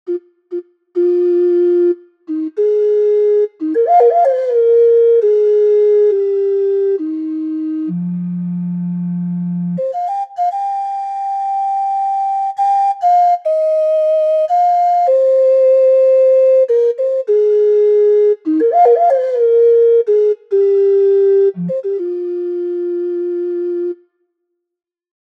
Ocarinas and Clay Flutes for Kontakt and Reason includes the haunting sounds of the native American flutes of north and central America. Four different clay flutes and three ocarinas, played so that they create sweet or sorrowful, straight, vibrato, or wild calls, screeches, or tonal or microtonal trills, or bird calls.
Solo Flute 1
Solo_Flute_Demo_1.mp3